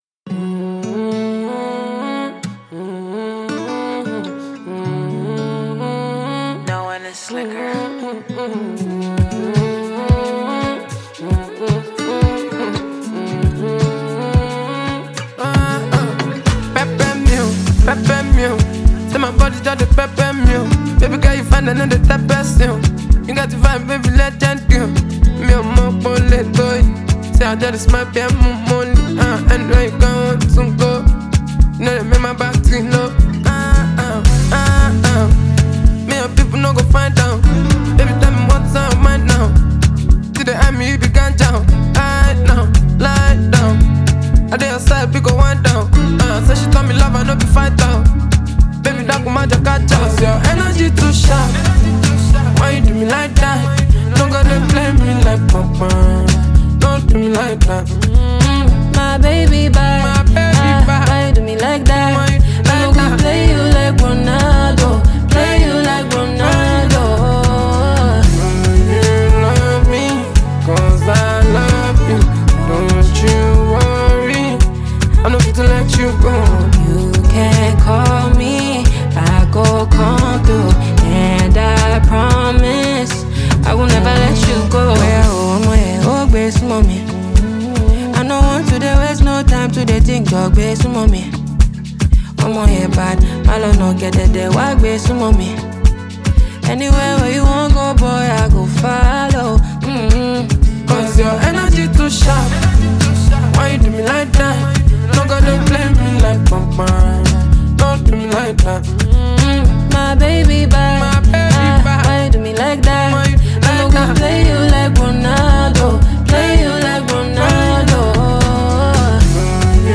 smooth, melodic vocals
signature street-smart delivery and catchy flows
playful atmosphere